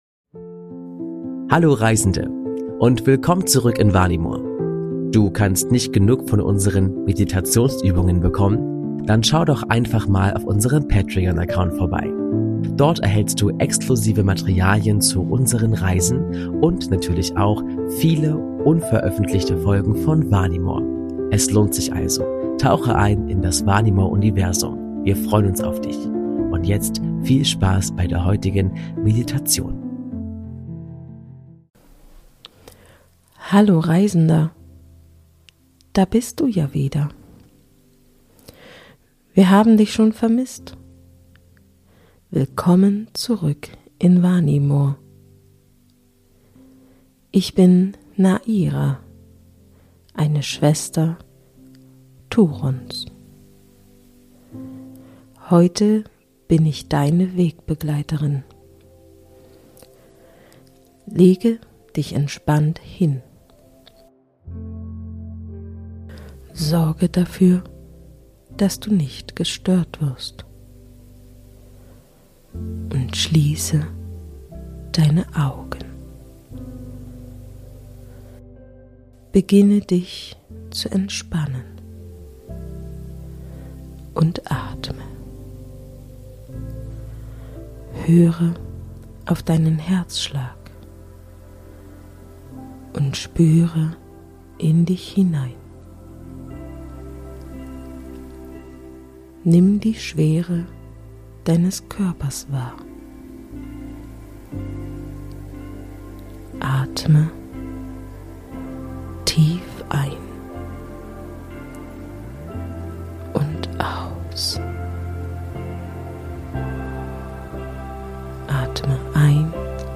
Entspannungsreise: In den Bergen von Vanimor ~ Vanimor - Seele des Friedens Podcast
Willkommen bei Vanimor – Seele des Friedens, deinem Meditations- und Entspannungspodcast, der dich auf eine Reise in eine andere Welt entführt. In dieser Episode begleiten wir dich zu den Bergen von Vanimor, wo du die Hektik des Alltags hinter dir lassen und neue Kraft schöpfen kannst.